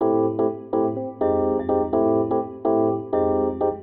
ORGAN001_VOCAL_125_A_SC3(R).wav